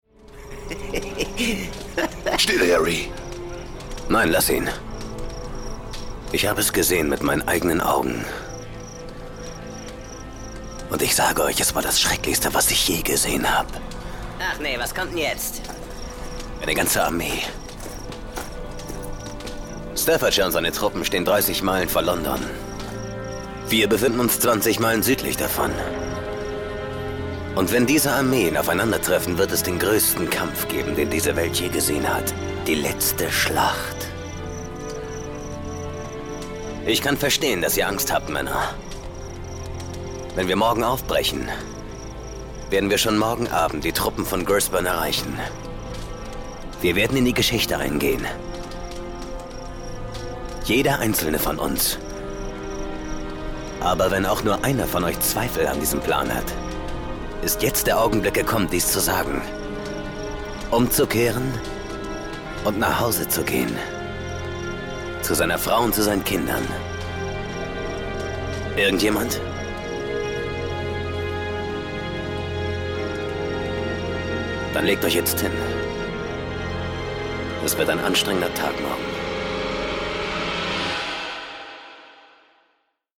sehr variabel
Mittel plus (35-65)
Audio Drama (Hörspiel)